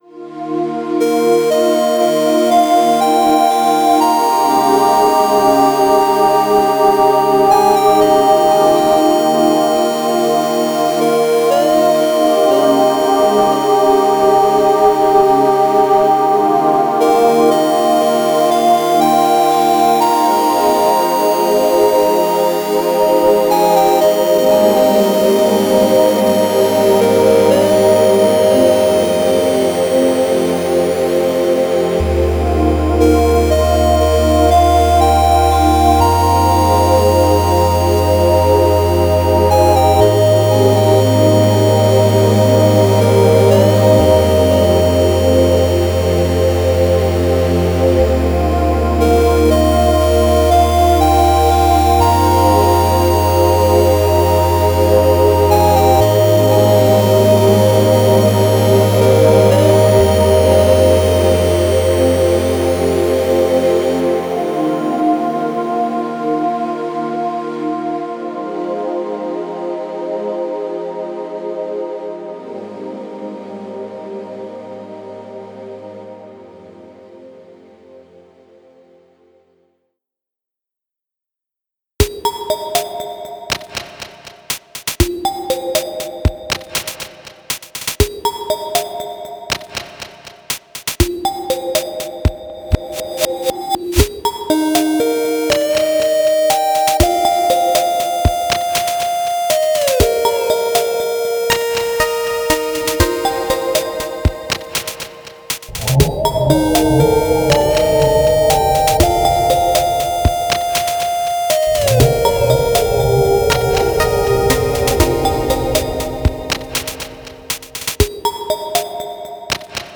how? very nice pads on the last track
Too tame to be a battle theme for me, but it's all taste.